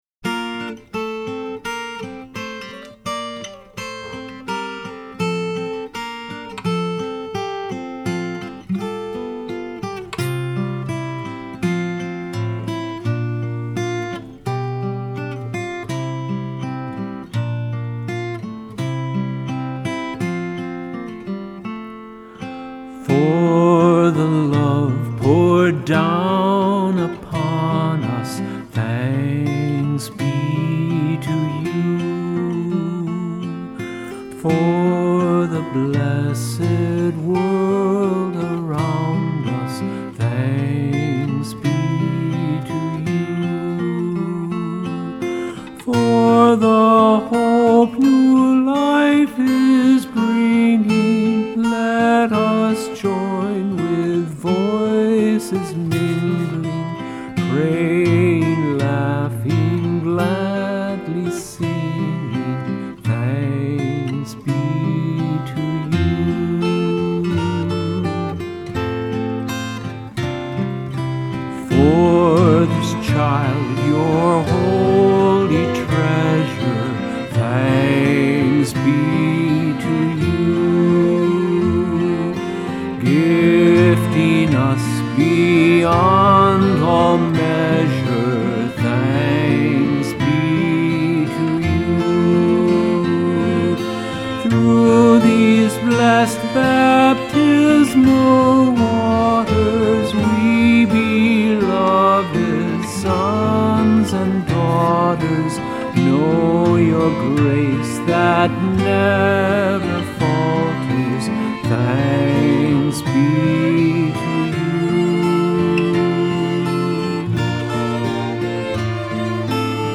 Accompaniment:      Keyboard
Music Category:      Christian
For cantors or soloists.